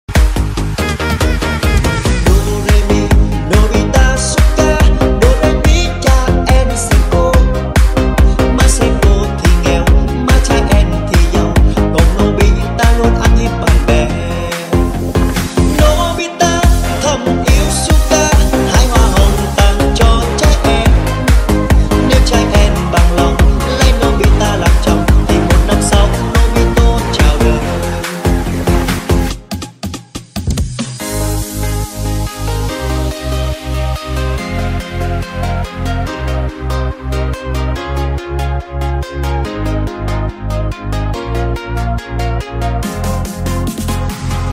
Nhạc remix hot tiktok